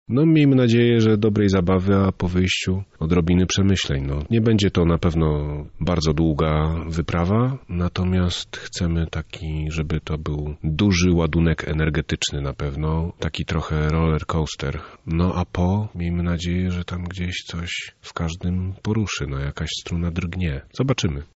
jeden z aktorów.